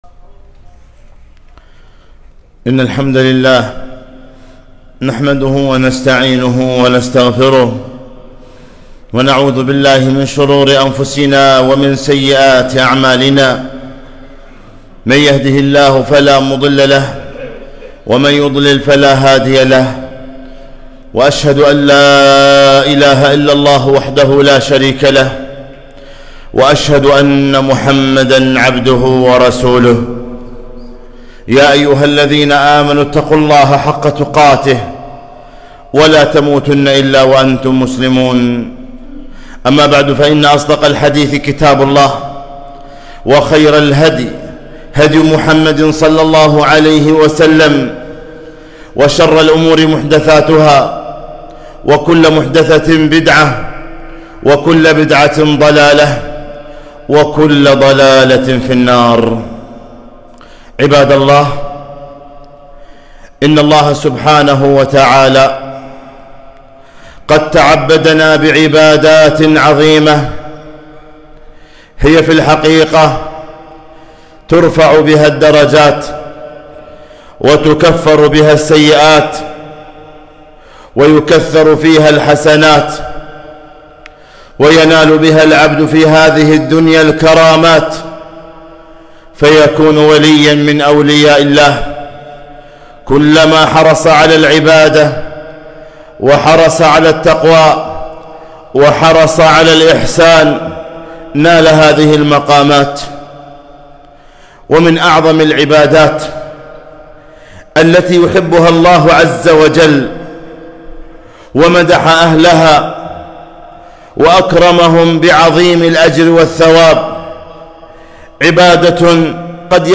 خطبة - أحب الناس إلى الله أنفعهم للناس